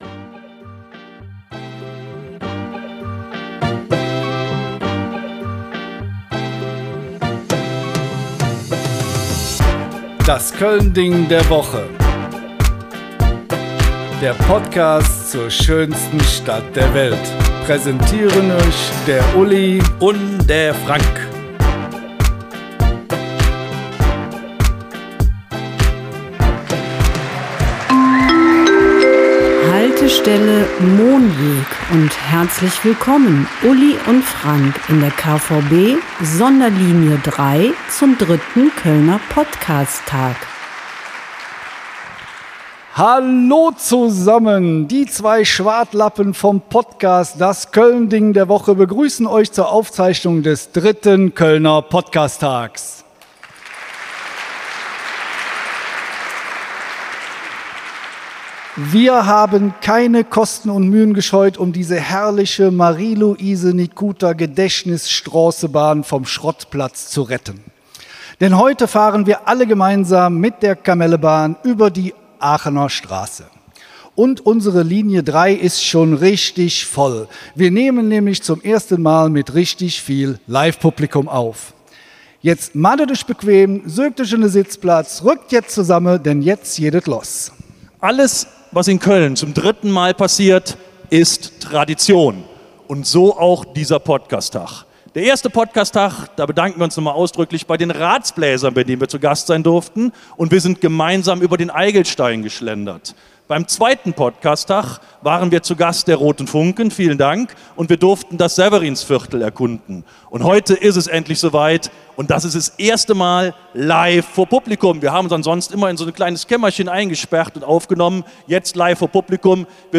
So wurde die Kirche St. Michael am Brüsseler Platz zum akustischen Zeitportal.